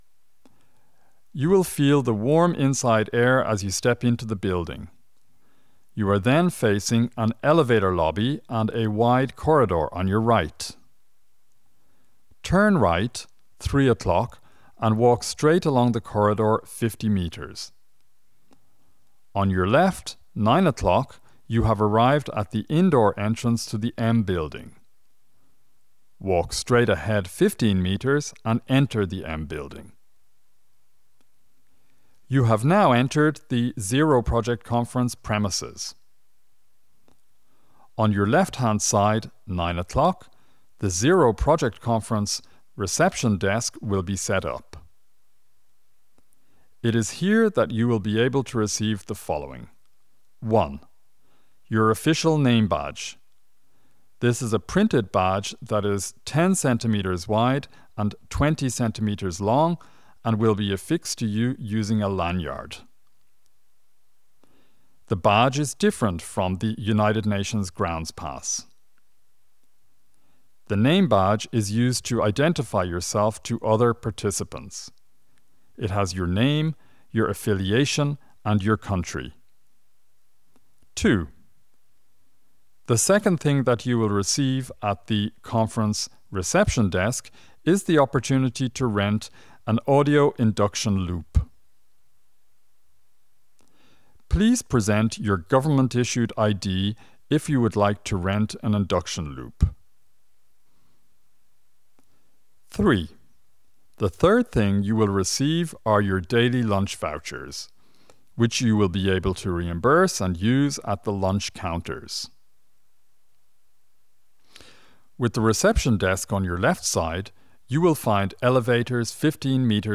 Audio Guides for orientation